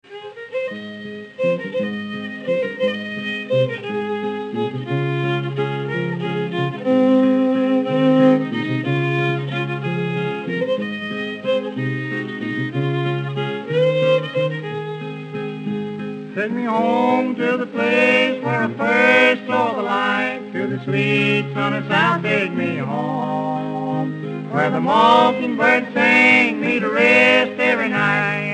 fiddle
guitar
vocals